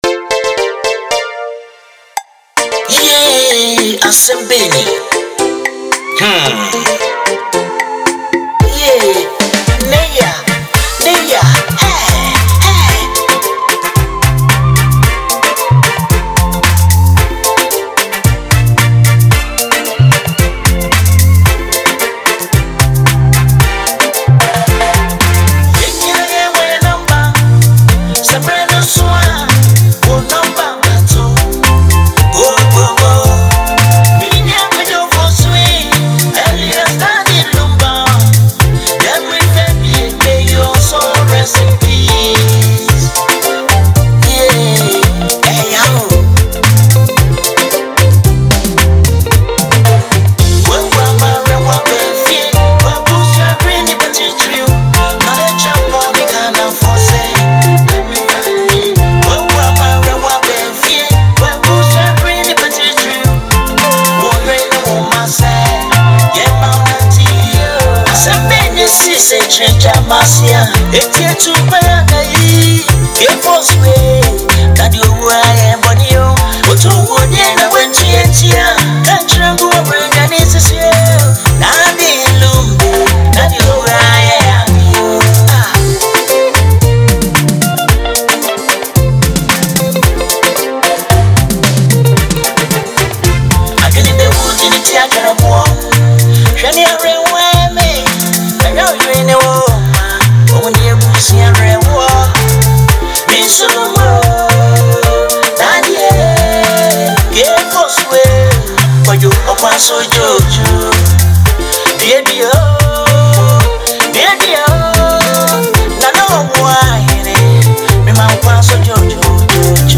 tribute song